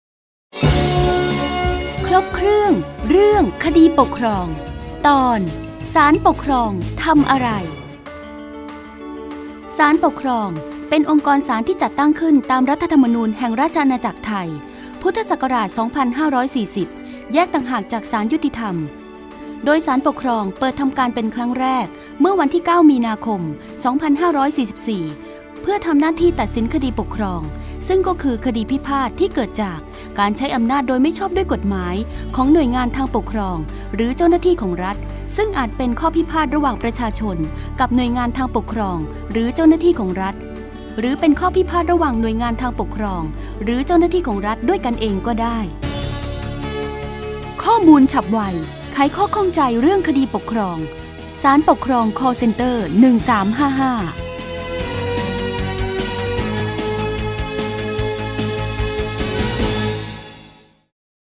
สารคดีวิทยุ ชุดครบเครื่องเรื่องคดีปกครอง ตอนศาลปกครองทำอะไร